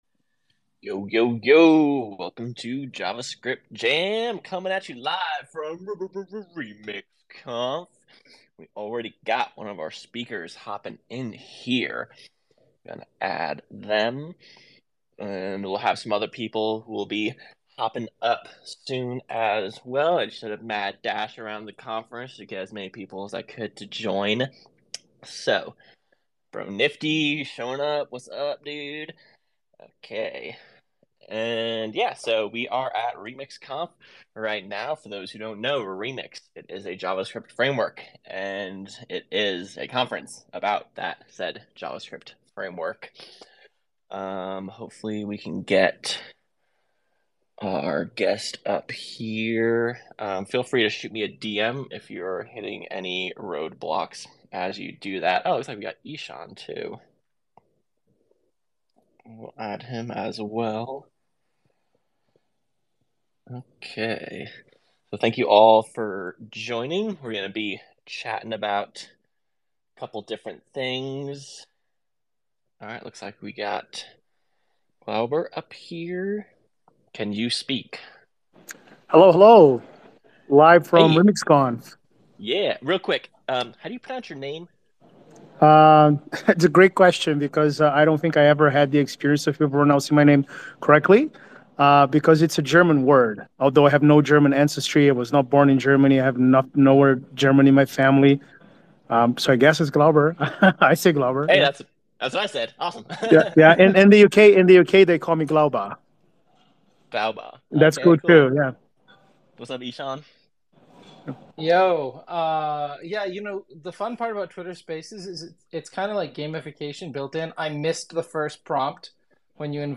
Live at Remix Conf 2023